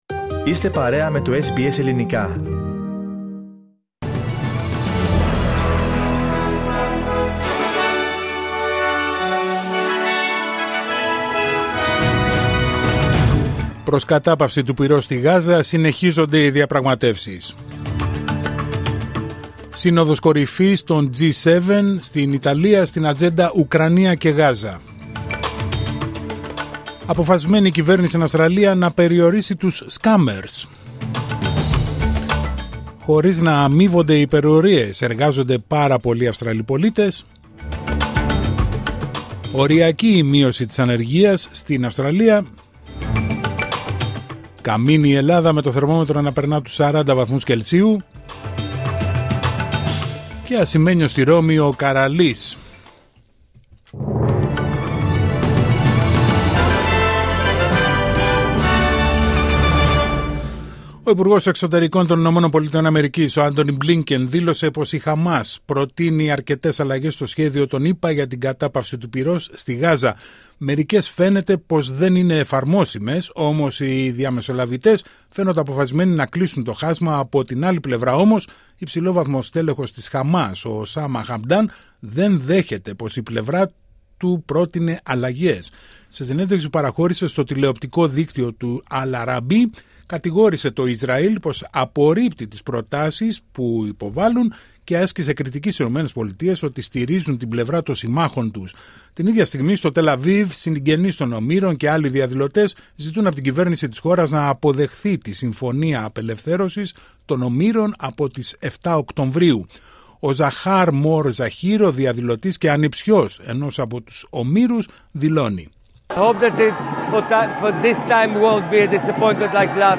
Δελτίο Ειδήσεων Πέμπτη 13 Ιουνίου